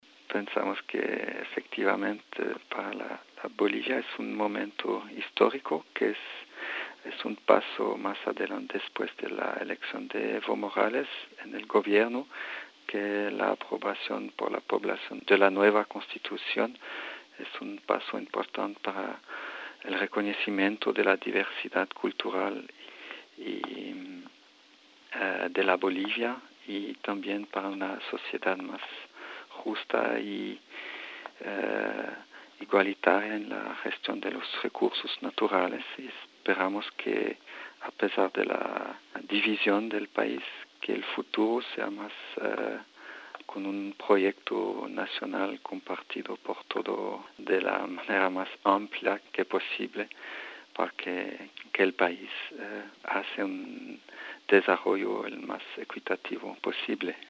en entrevista con swissinfo.